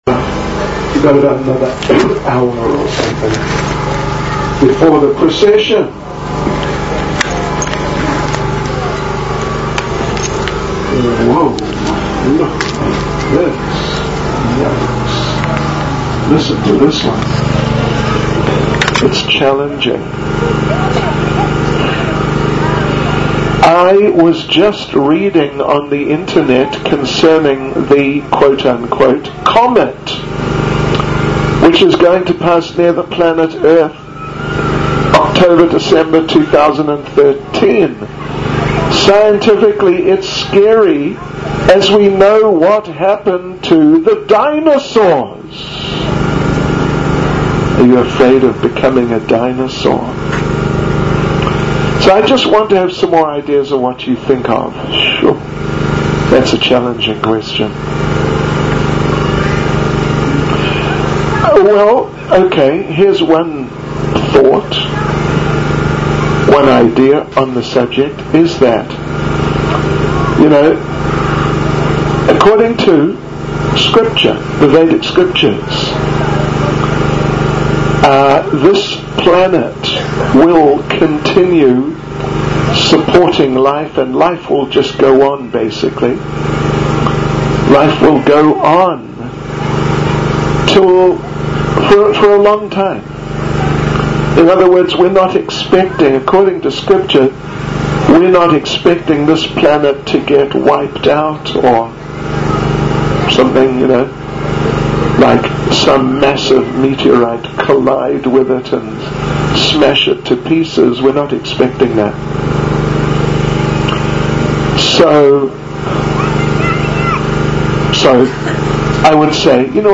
qa-cape-town-ratha-yatra-2012